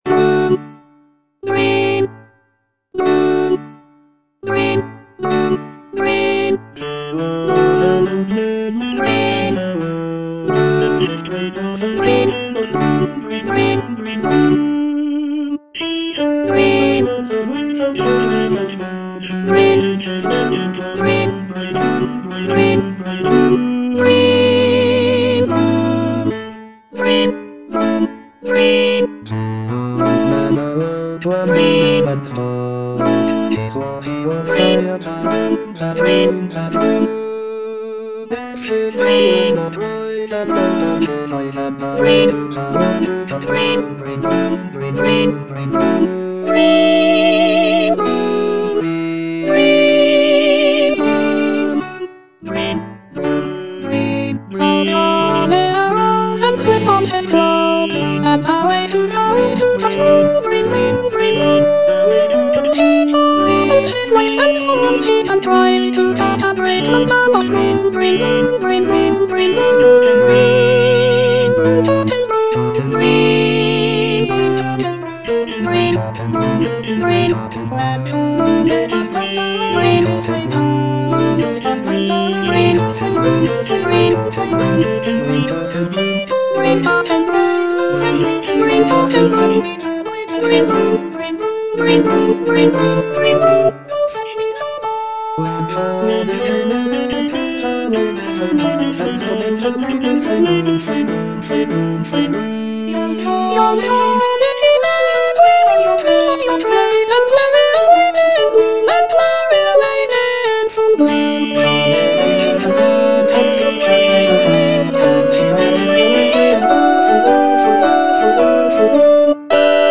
Soprano I (Emphasised voice and other voices)